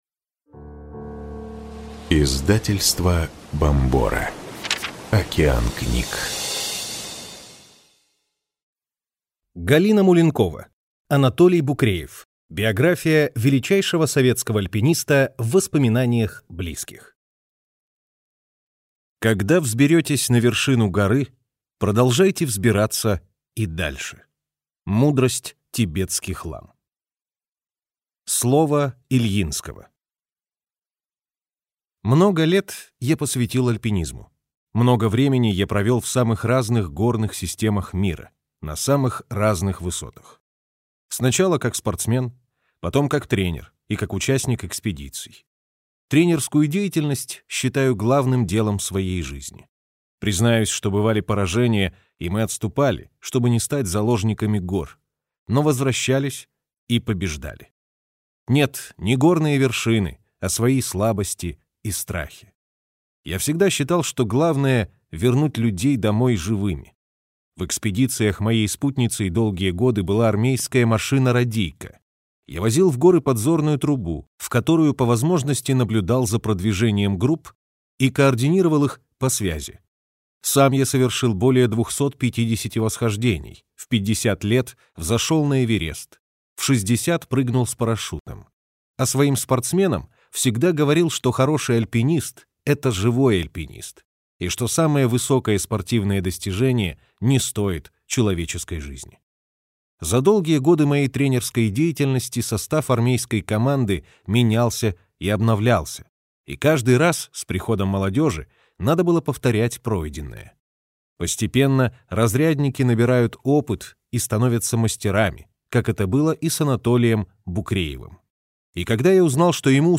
Аудиокнига Анатолий Букреев. Биография величайшего советского альпиниста в воспоминаниях близких | Библиотека аудиокниг